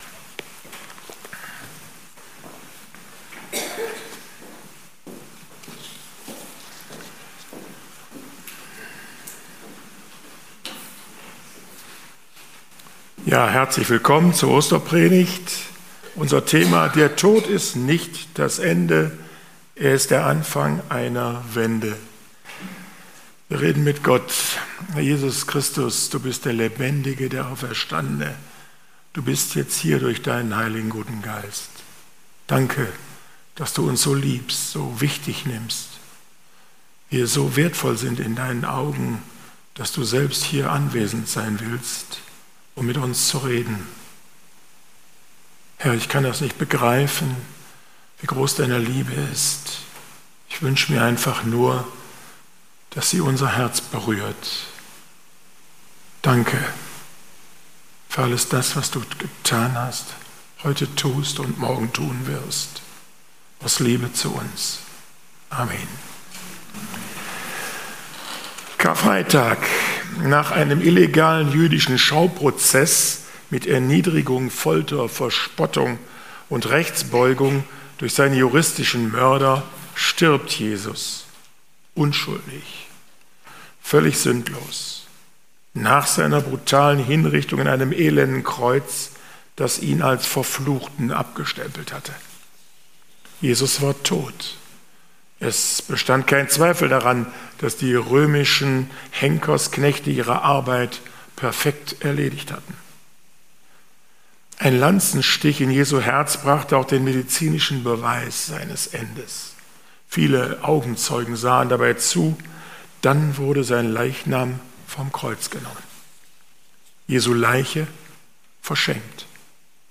31.03.2024 ~ Predigten - FeG Steinbach Podcast